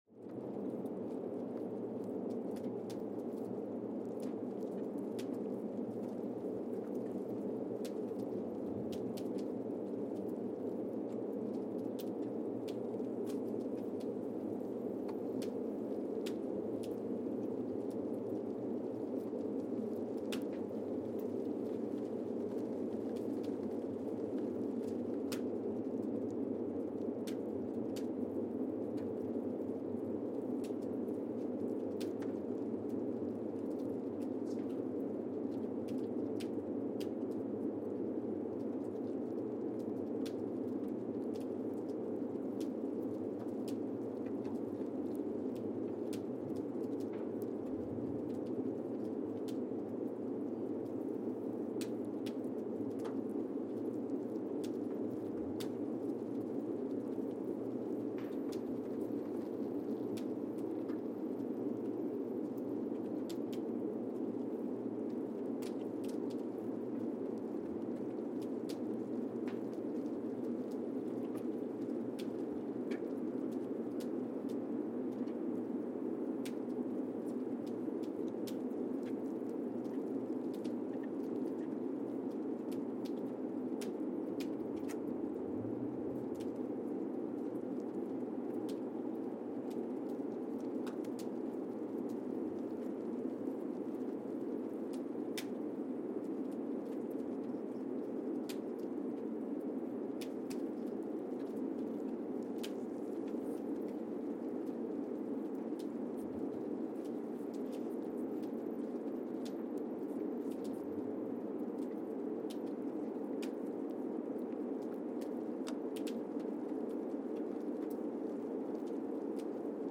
Casey, Antarctica (seismic) archived on September 9, 2020
Sensor : Streckheisen STS-1VBB
Speedup : ×1,800 (transposed up about 11 octaves)
Loop duration (audio) : 05:36 (stereo)
SoX post-processing : highpass -2 90 highpass -2 90